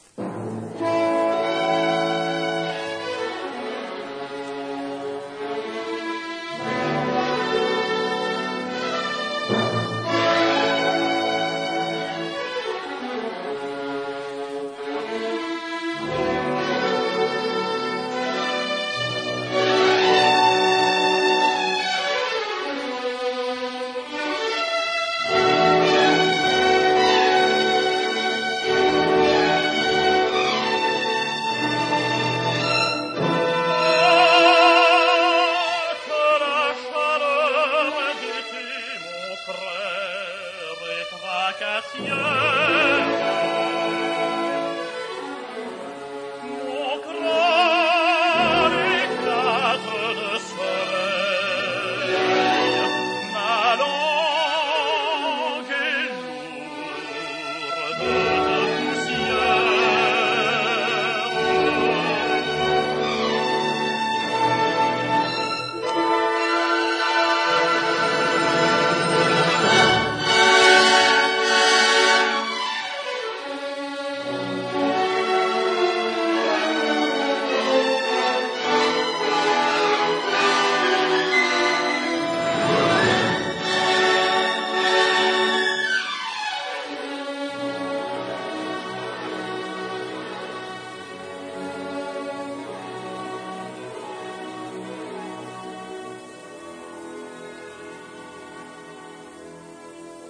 這兩套曲目都是很好的範例，層次相當清楚，動態幾乎不壓縮。
可以很清楚地區分不同樂器所在、與其質感。